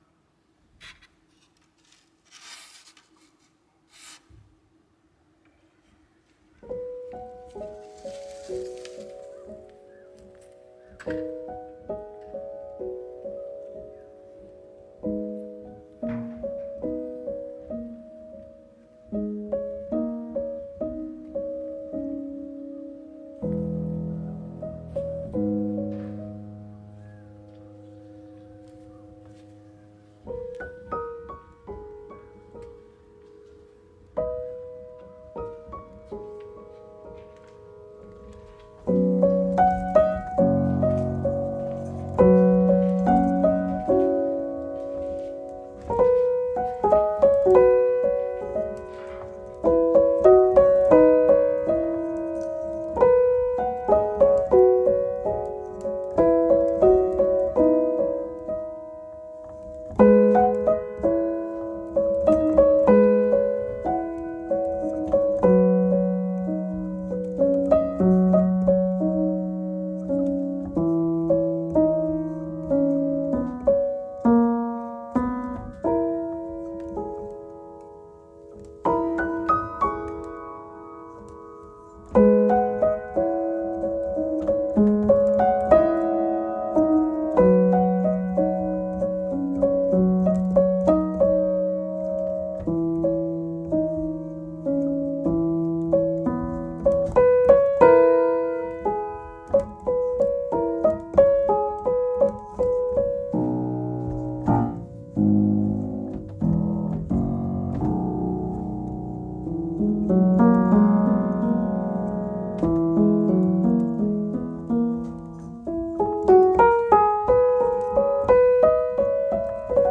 The piano, though a bit out of tune, is in good shape.